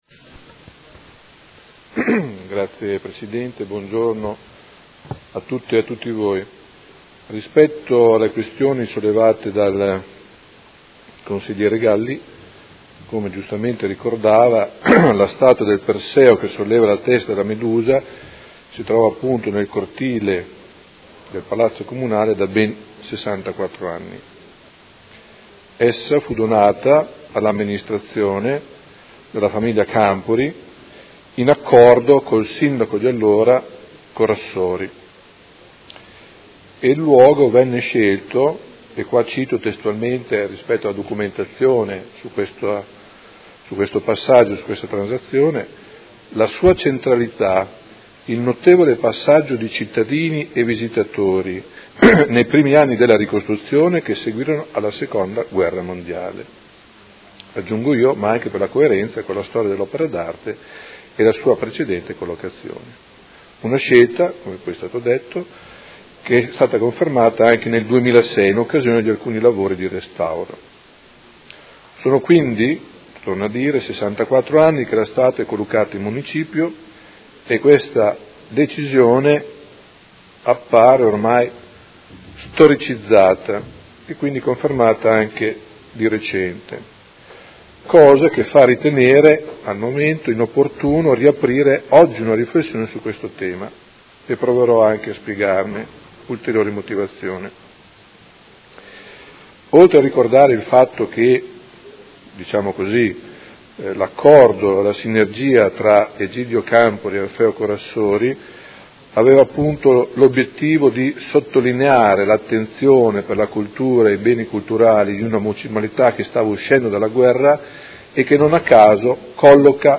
Seduta del 20/04/2016. Risponde a interrogazione del Consigliere Galli (F.I.) avente per oggetto: Statua del Perseo nel cortile del palazzo Comunale; visto che il cortile viene usato come parcheggio non sarebbe meglio identificare una postazione di maggior visibilità e attrattività per i turisti?